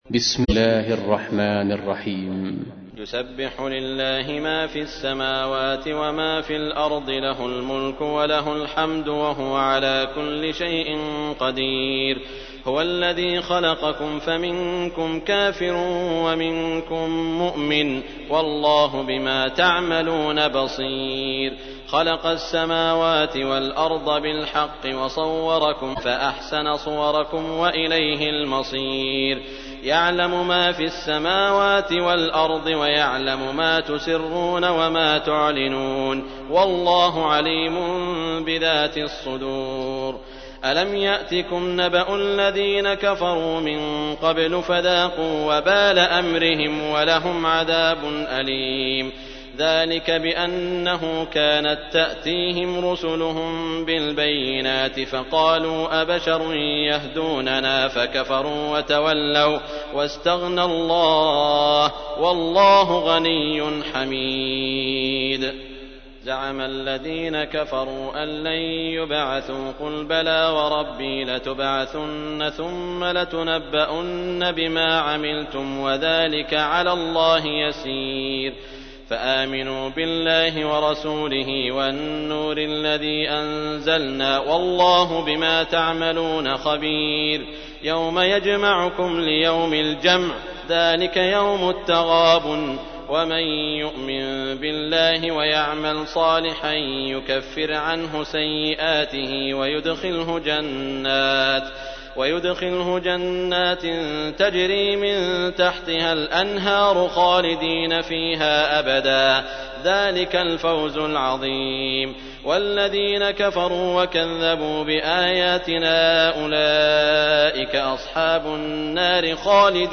تحميل : 64. سورة التغابن / القارئ سعود الشريم / القرآن الكريم / موقع يا حسين